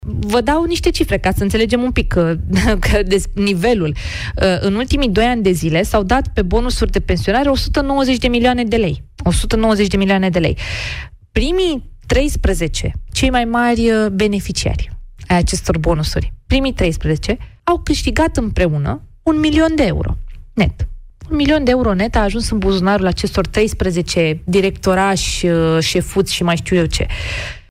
Ministrul Mediului, Diana Buzoianu, prezentă în emisiunea Piața Victoriei la Europa FM, a vorbit despre neregulile din această instituție.